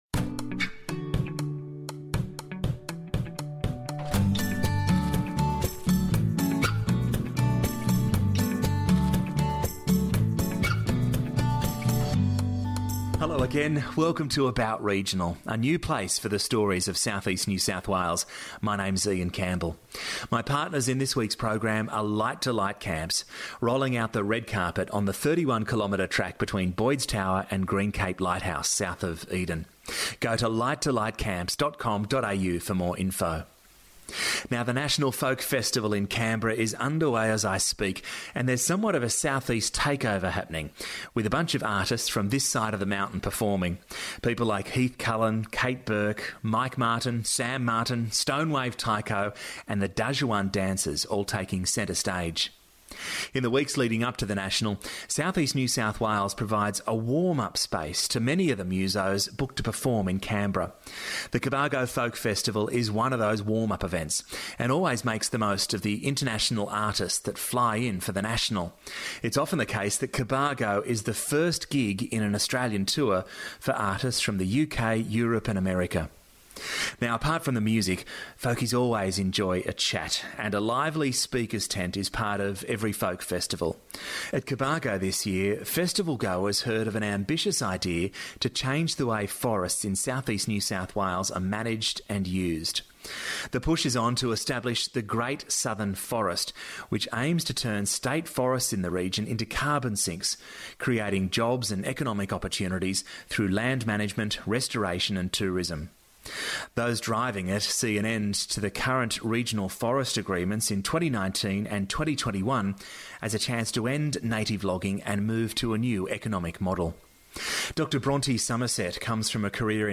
Apart from the music, folkies enjoy a chat and a lively speakers tents is part of every folk festival. At Cobargo this year, festival goers heard of an ambitious idea to change the way forests in South East NSW are managed and used. The push to establish The Great Southern Forest aims to turn State Forests in the region into carbon sinks – creating jobs and economic opportunities through land management, restoration and tourism.